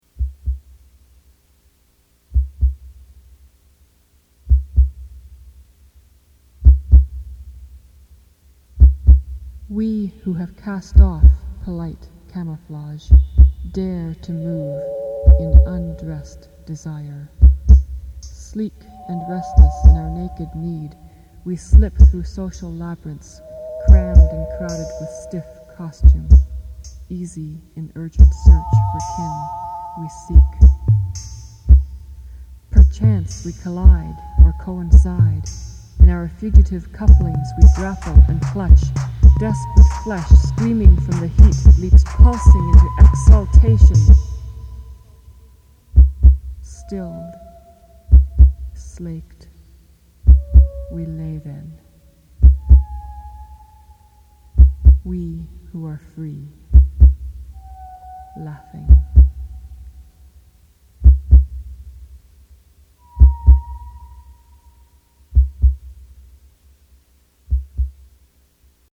取り留めもなく夢の風景を辿る、夢日記仕立てのポエトリーシンセポップ。
キーワード：宅録　乙女　ミニマル